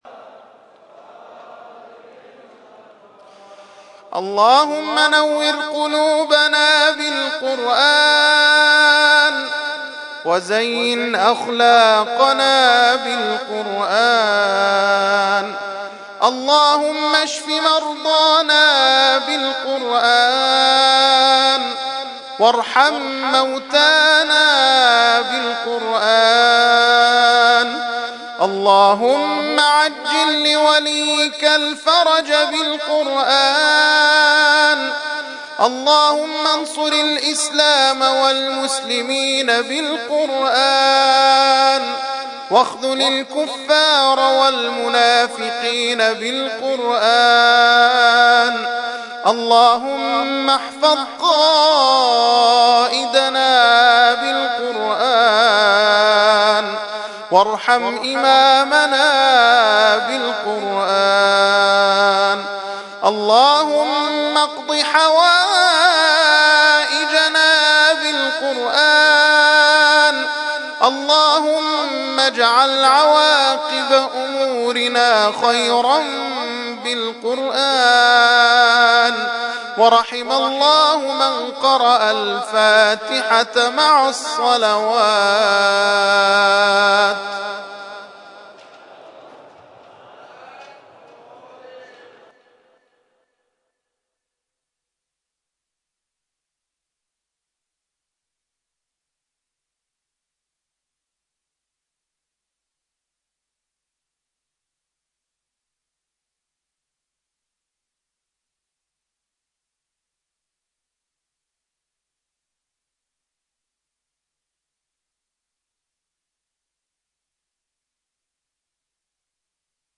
ترتیل خوانی جزء ۲۵ قرآن کریم در سال ۱۳۹۳